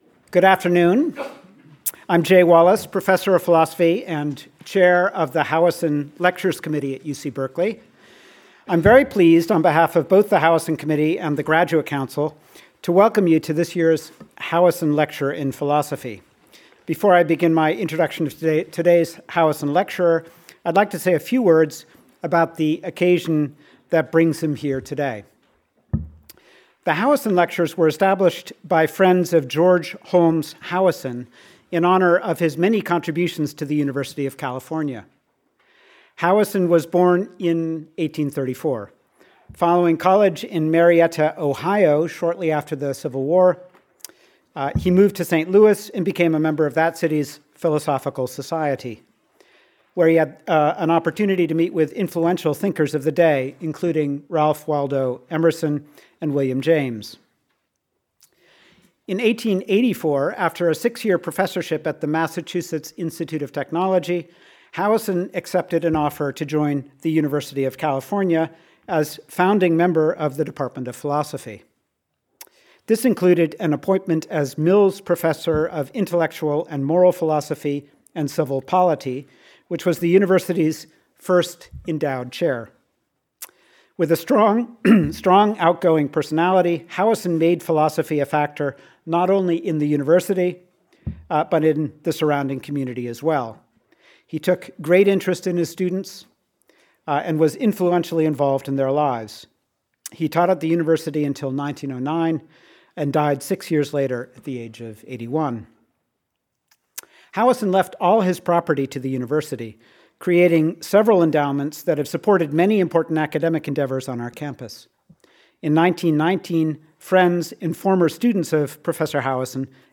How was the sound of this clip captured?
Continued Alumni House, Toll Room - UC Berkeley Campus Berkeley Graduate Lectures [email protected] false MM/DD/YYYY